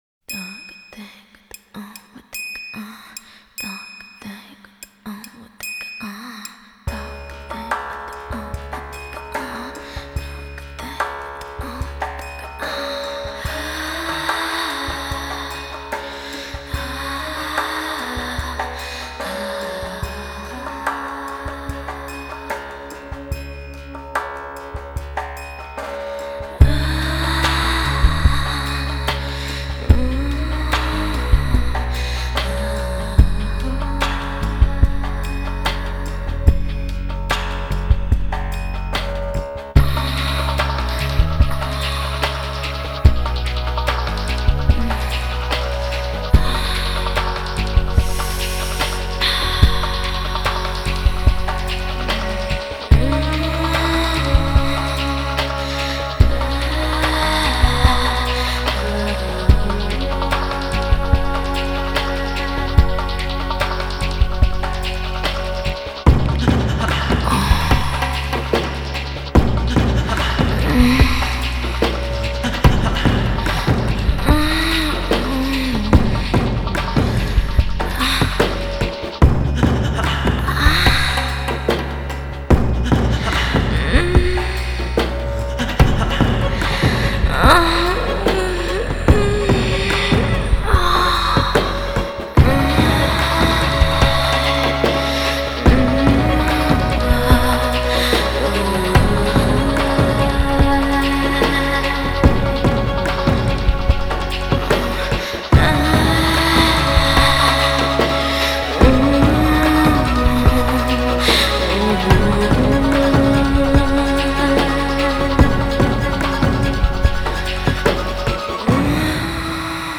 Artist: Instrumental,